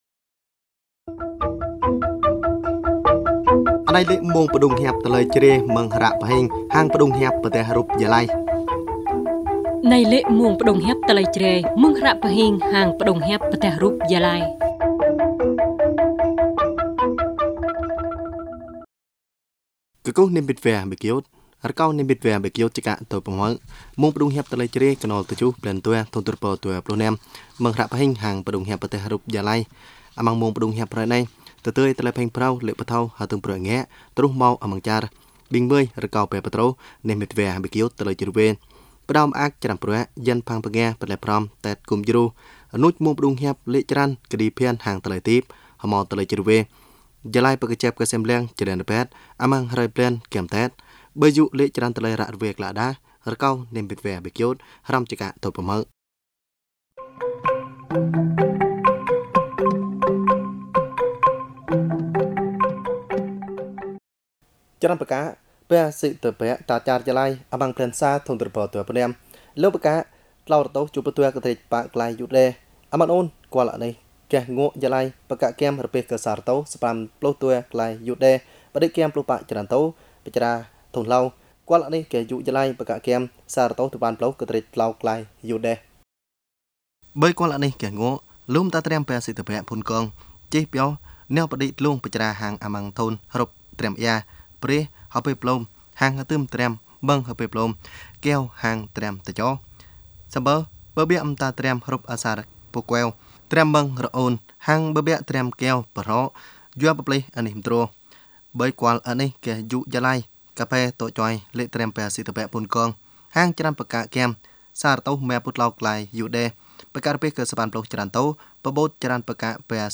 Thời sự Jrai